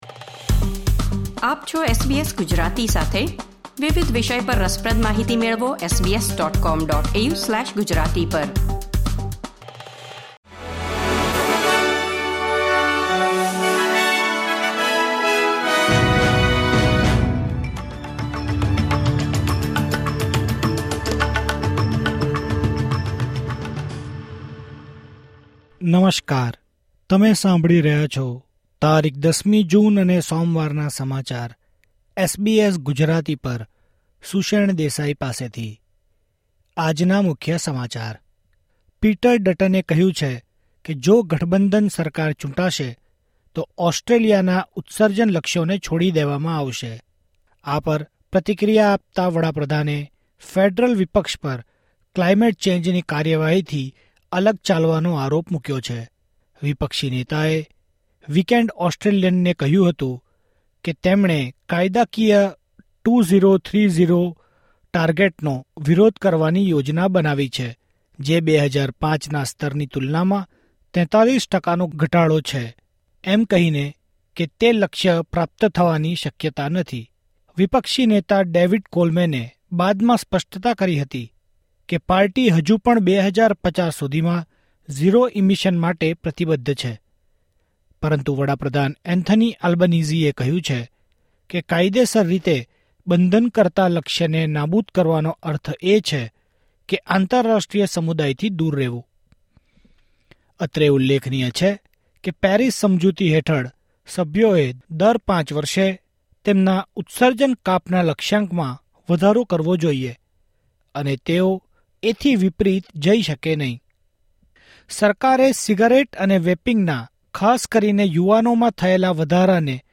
SBS Gujarati News Bulletin 10 June 2024